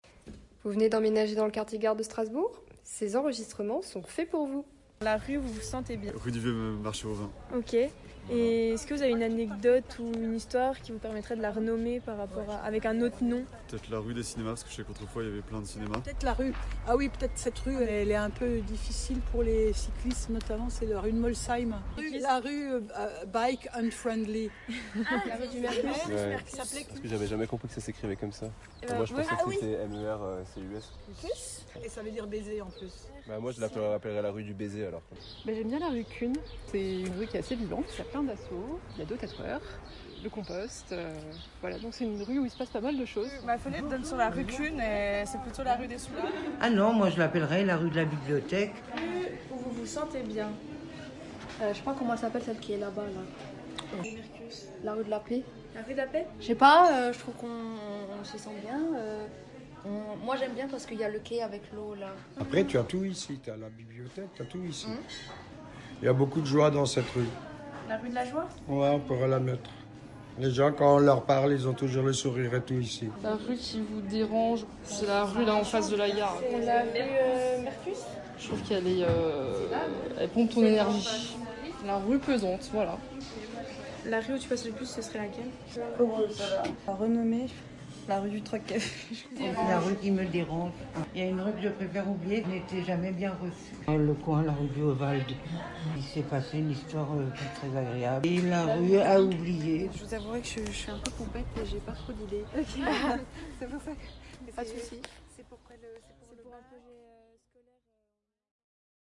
Afin d’accueillir les nouveaux habitants du quartier gare de Strasbourg, il nous a été demandé de créer une bande sonore récoltant les témoignages des habitants actuels du quartier.